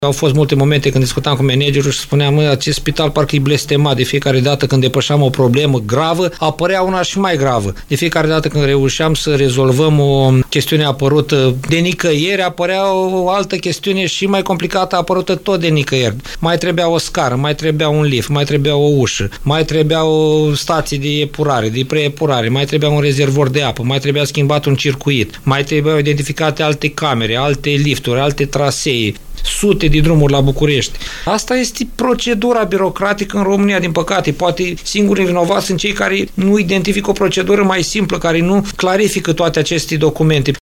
El a declarat postului nostru de radio că modificările succesive de legislație au impus noi investiții și schimbări ale proiectului.